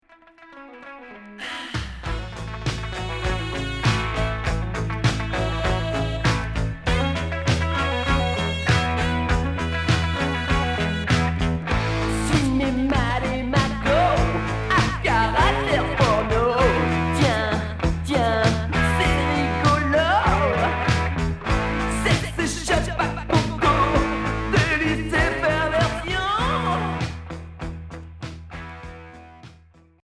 Rock new wave Premier 45t retour à l'accueil